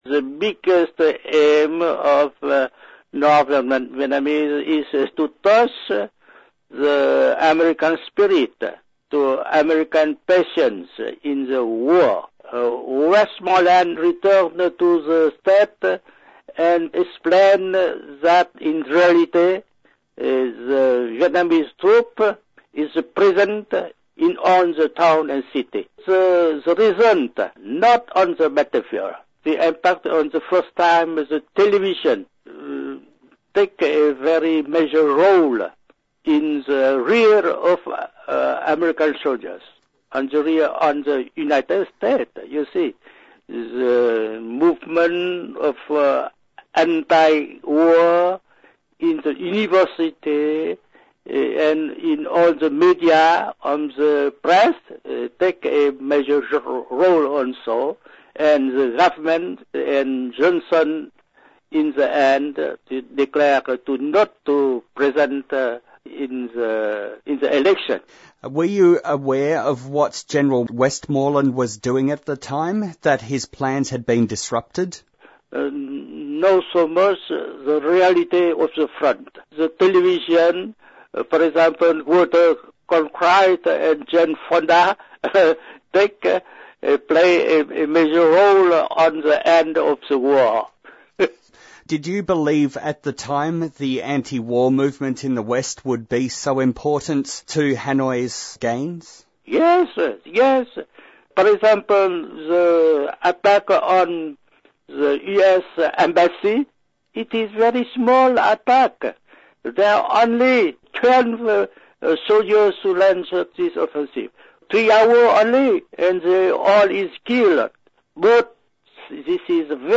Speaker – Colonel Bui Tin, former top commander of the Communist north during the Vietnam war.